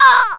jess_hurt_02.wav